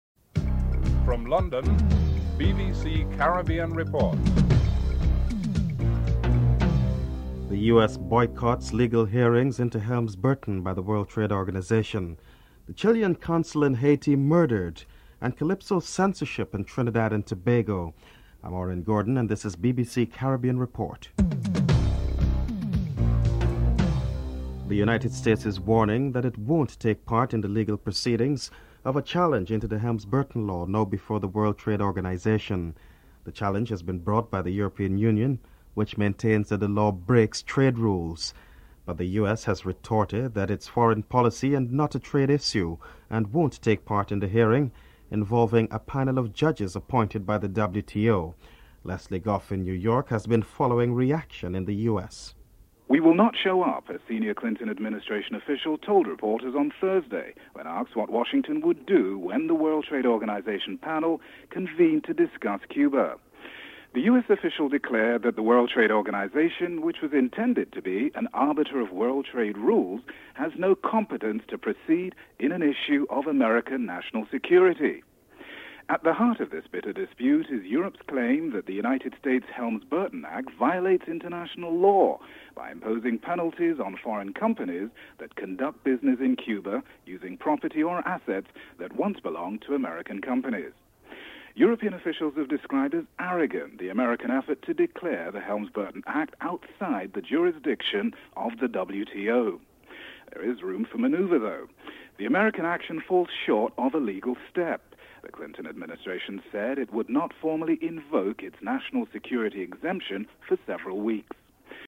1. Headlines (00:00-00:23)
3. Mystery surrounding a group of Dominican men who are missing. Dominica Police Commissioner, Desmond Blanchard is interviewed (02:35-05:51)